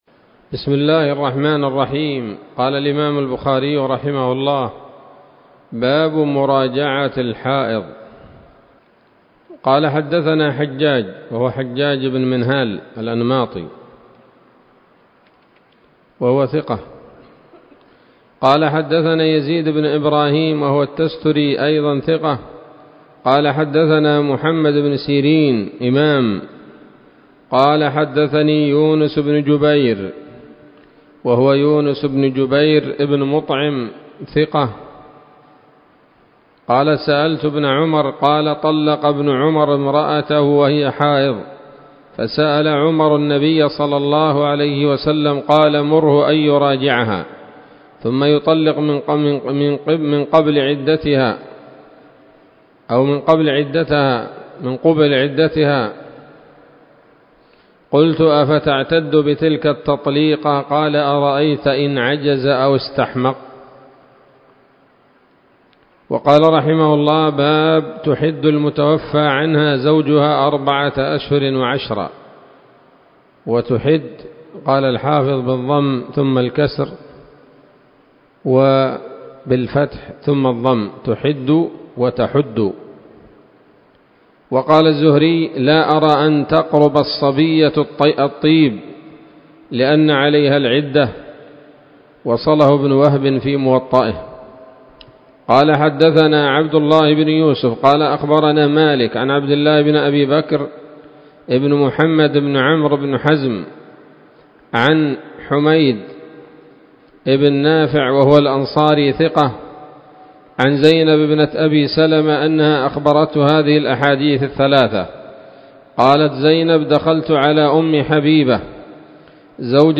الدرس الخامس والثلاثون من كتاب الطلاق من صحيح الإمام البخاري